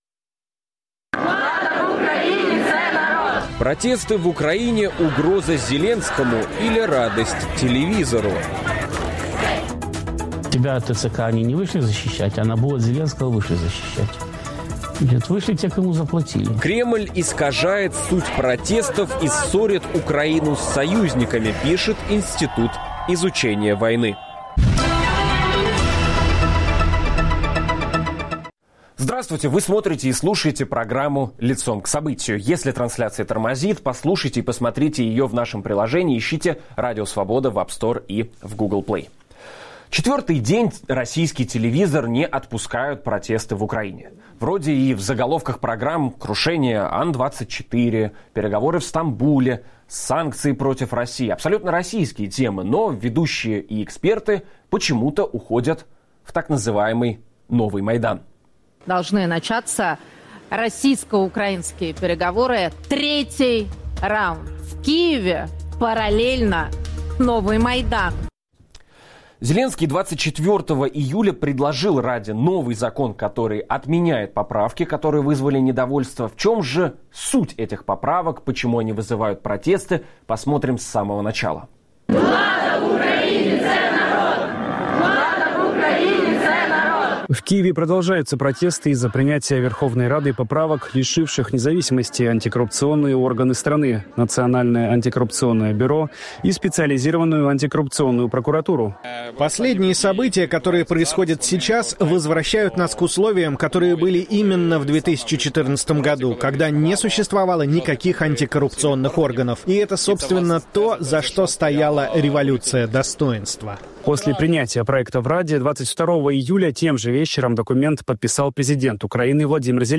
обсуждаем в программе "Лицом к событию" с политологами